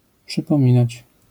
wymowa:
IPA[ˌpʃɨpɔ̃ˈmʲĩnat͡ɕ], AS[pšypõmʹĩnać], zjawiska fonetyczne: zmięk.utr. dźw.nazal.akc. pob. ?/i